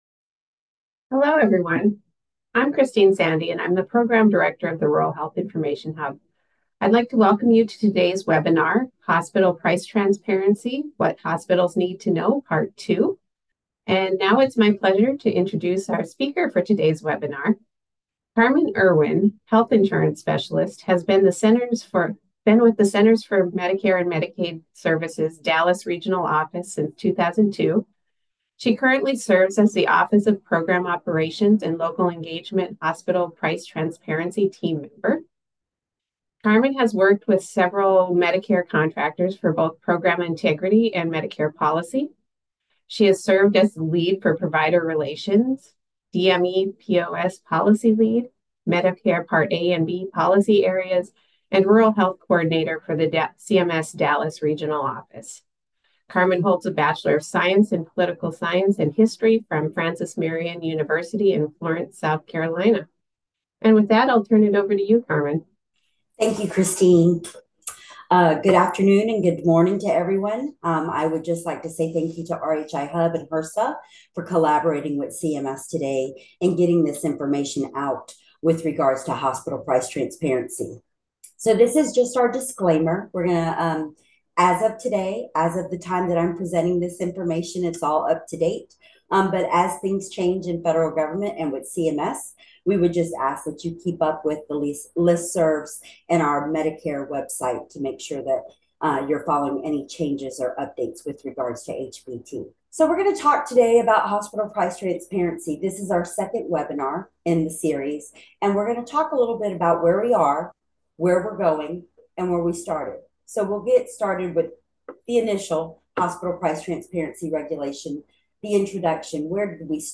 A webinar from the Rural Health Information Hub.